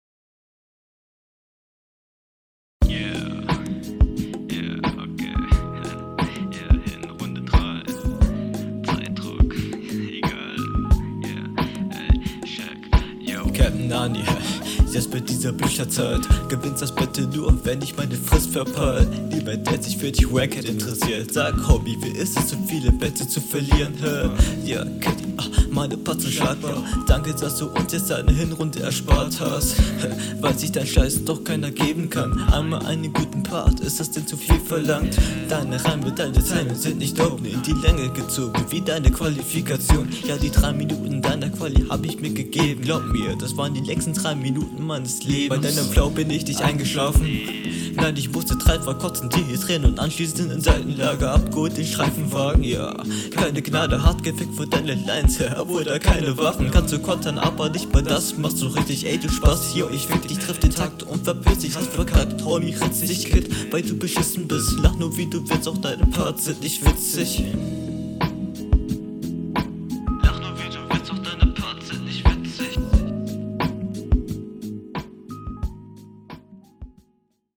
Der Stimmeinsatz klingt jetzt etwas zu nasal und ist etwas anstengend.
Flow: ➨ Klingt absolut chillig. ➨ Beat, Stimmlage, Style und Betonung sehr passend zueinander.